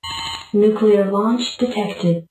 launch-sound.ogg